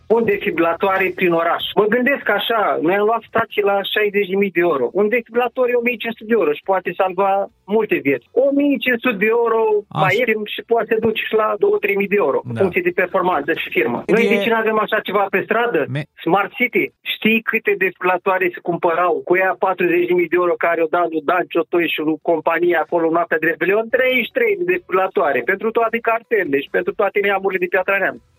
INSERT AUDIO – CETĂȚEAN (voce stradă):